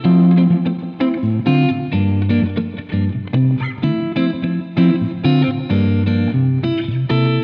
电吉他psyched...和弦1
描述：一些迷幻的电吉他和弦（用Fender Stratocaster吉他演奏）。
Tag: 和弦 电动 芬德 吉他